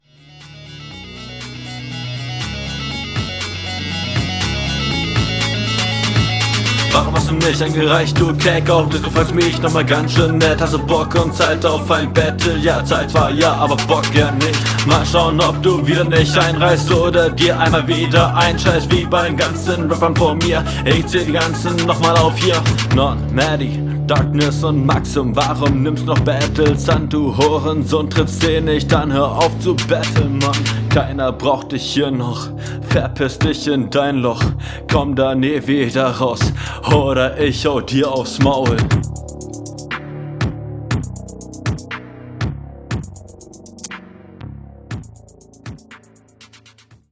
Du verstolperst dich am Anfang ganzschön im Flow.